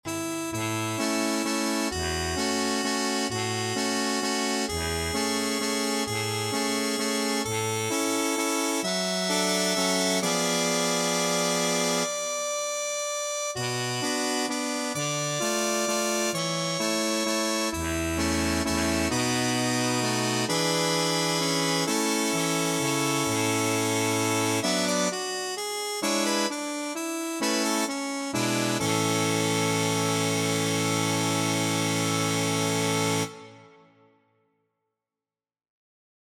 Key written in: A Major
How many parts: 4
Type: SATB
All Parts mix: